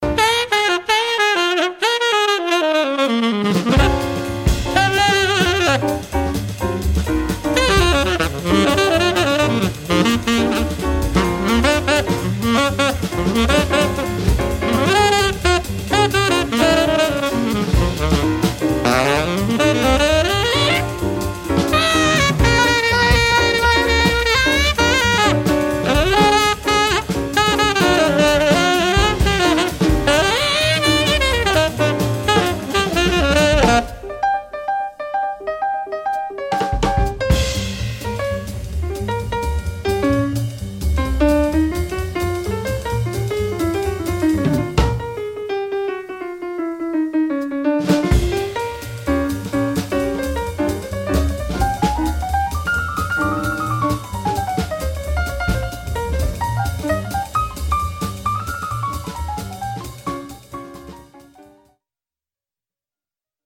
saxophone ténor
piano
contrebasse
batterie